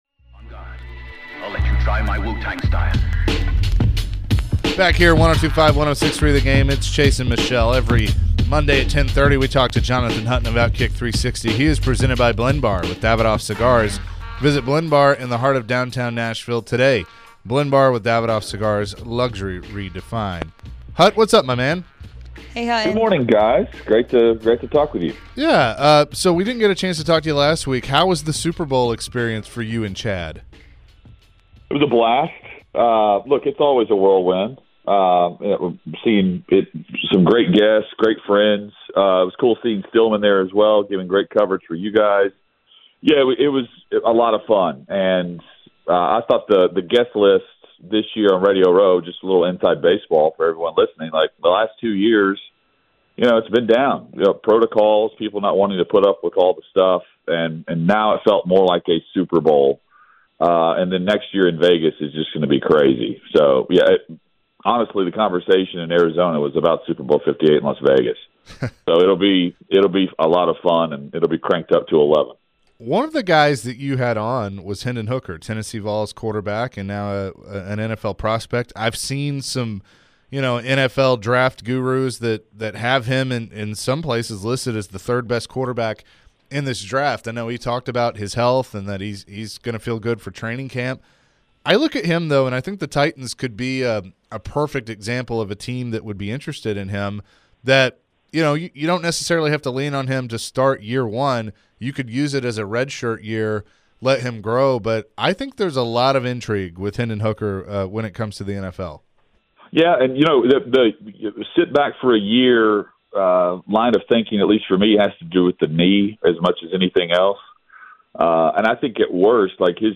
Interview (2-20-23)